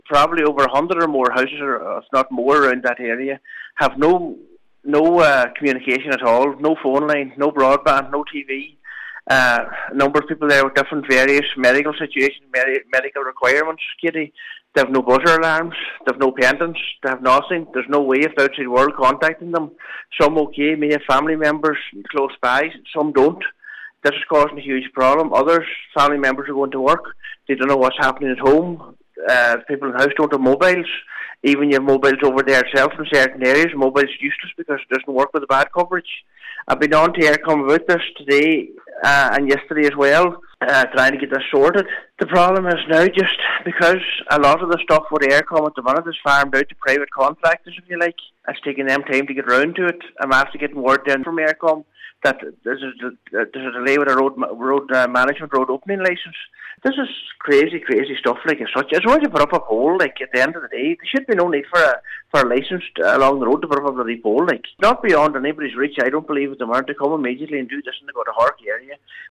He says the fact that people in rural Donegal have been abandoned in this way is unacceptable: